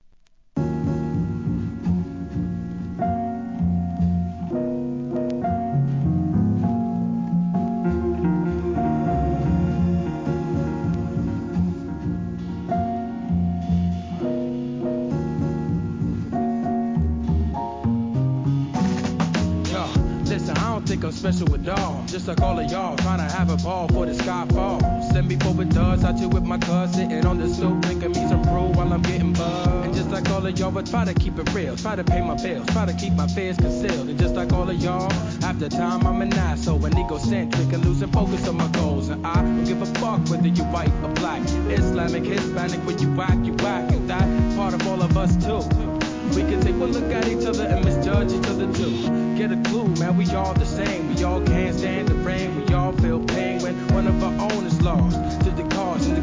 HIP HOP/R&B
オランダからHIP HOP〜JAZZがHIGHセンスにクロスオーバーする2007年作品!!